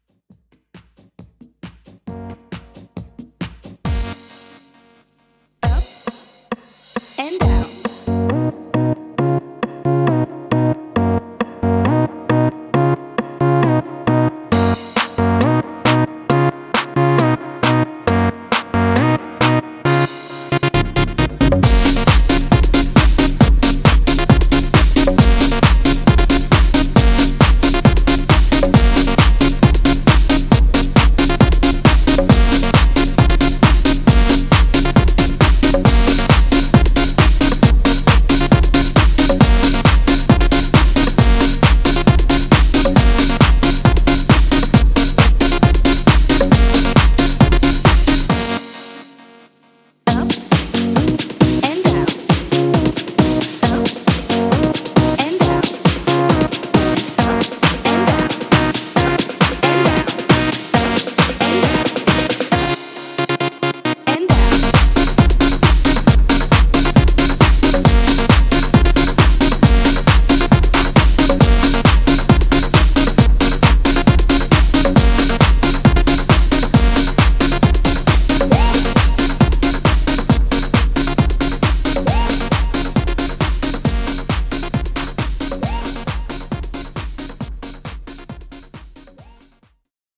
House ReDrum)Date Added